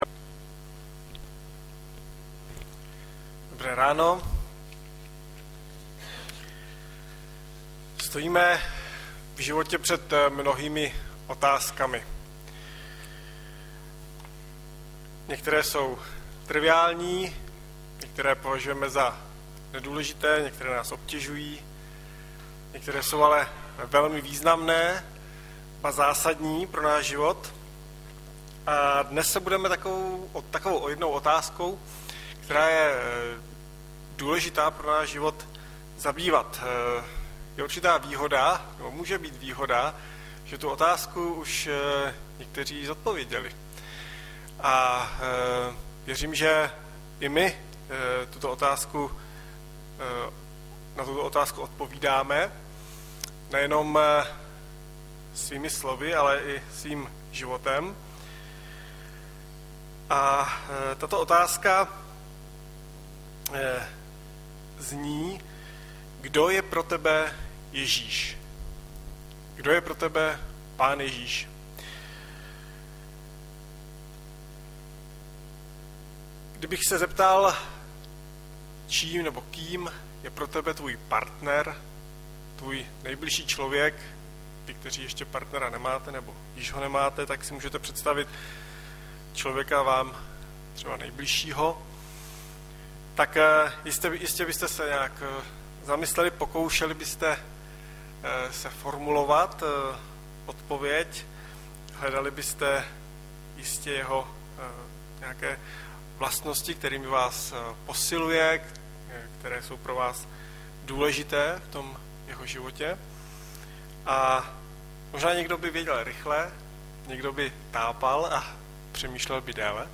- Mat 16,13-18 Audiozáznam kázání si můžete také uložit do PC na tomto odkazu.